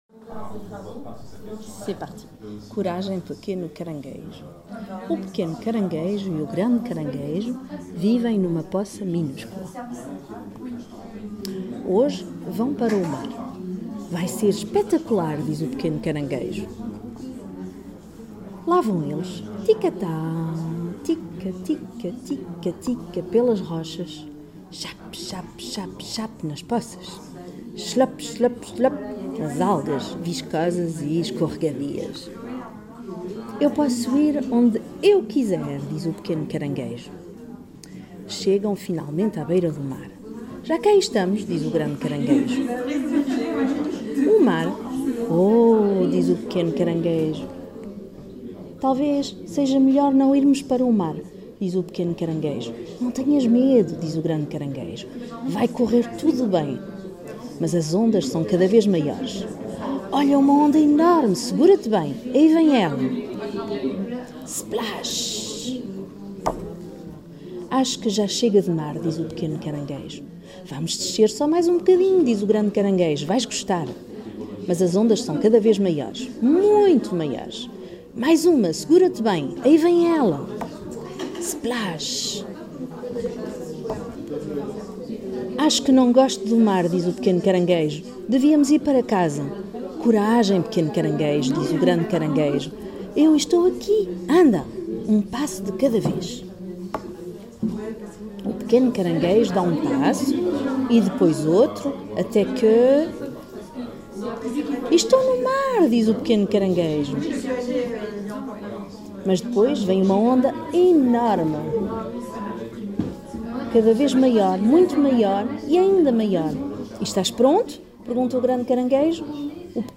Cet espace invitait également à la flânerie, de nombreuses personnes se sont installées pour découvrir les albums et certaines nous ont fait le cadeau d’une lecture dans différentes langues.
Voici la lecture de « pas de panique petit crabe » en portugais